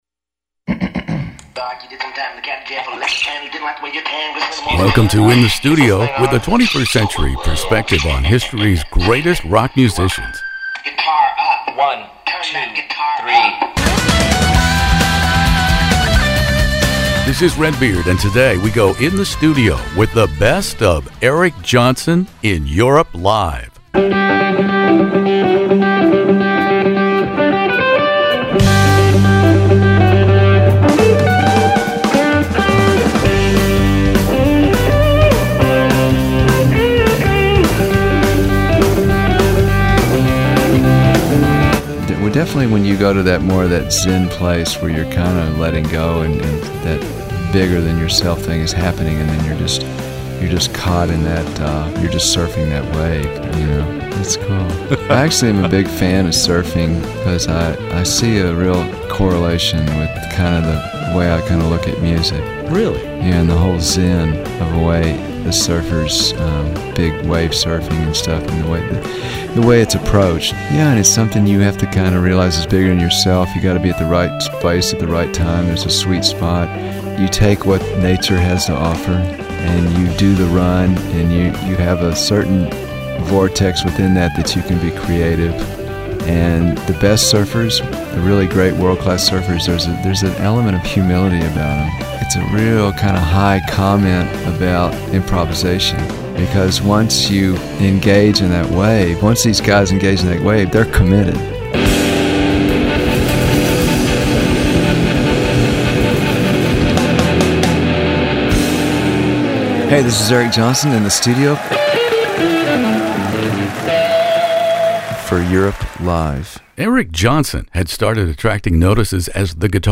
One of the world's largest classic rock interview archives, from ACDC to ZZ Top, by award-winning radio personality Redbeard.
eric-johnson-in-the-studio-europe-live.mp3